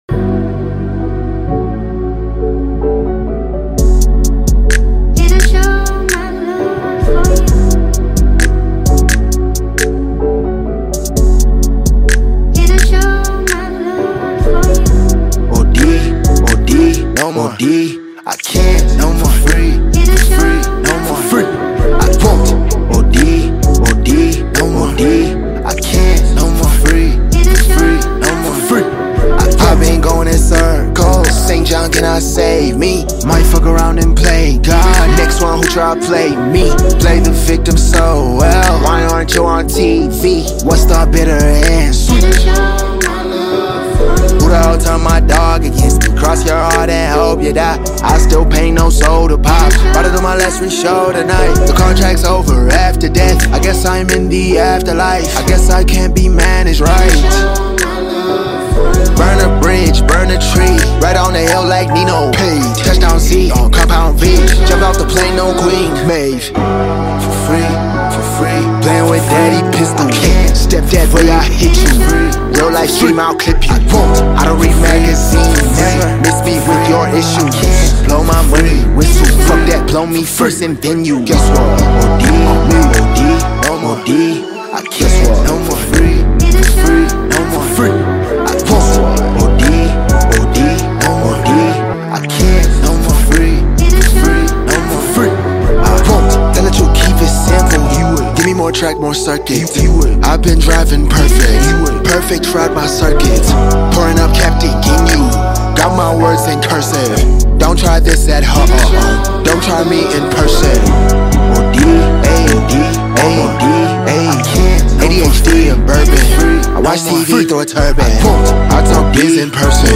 Exceptionally talented singer